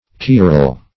Search Result for " querele" : The Collaborative International Dictionary of English v.0.48: Querele \Quer"ele\, n. [See 2d Quarrel .]